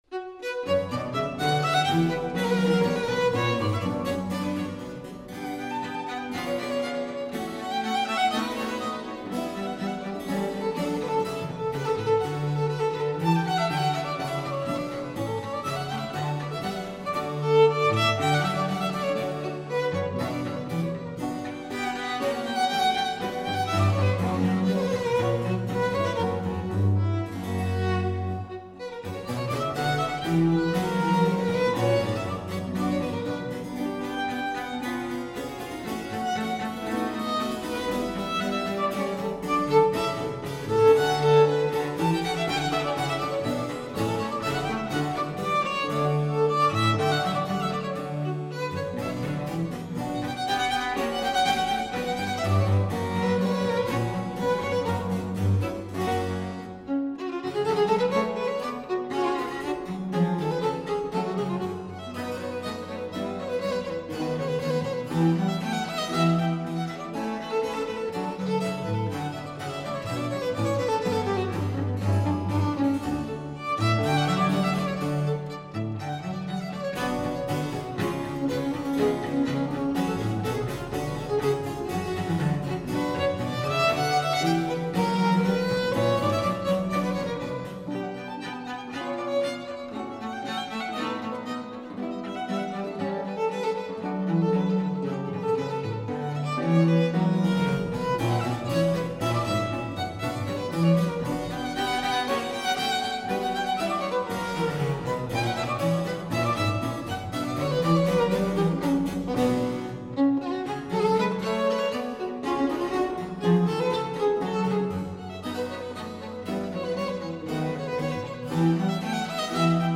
Incontro a Mantova con il violinista italiano